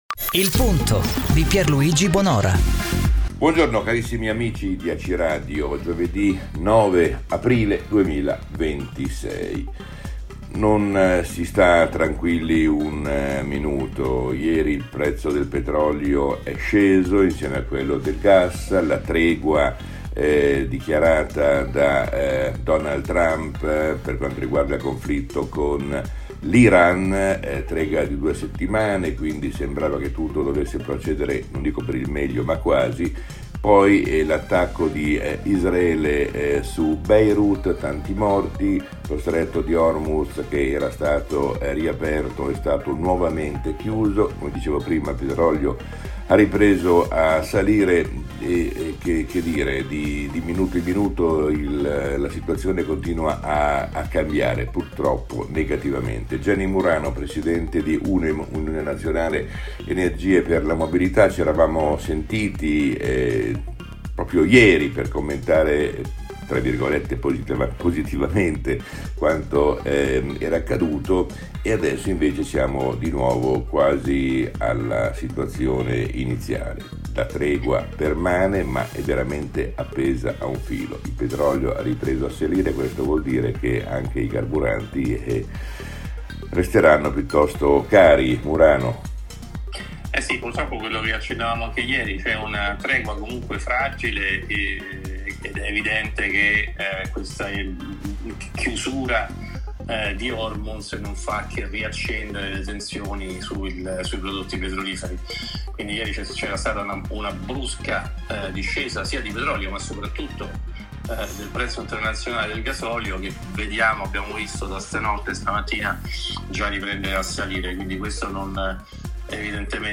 AciRadio, Intervista